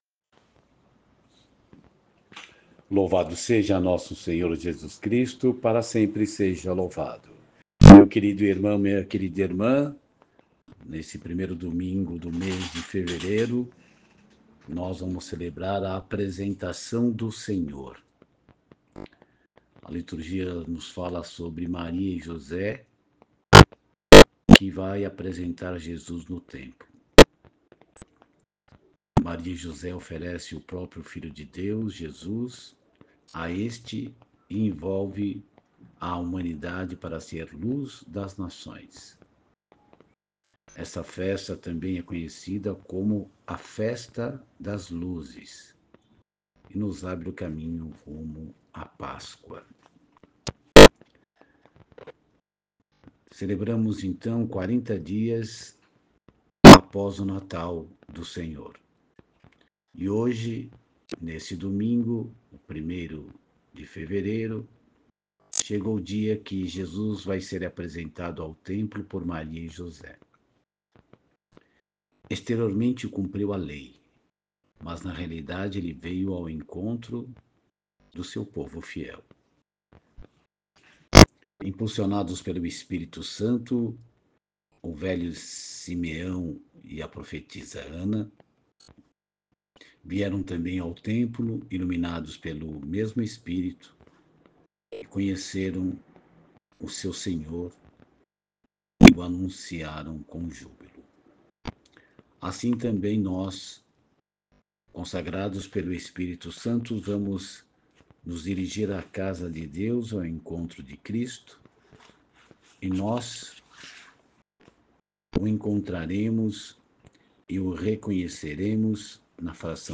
Meditação e Reflexão da Apresentação do Senhor. Ano C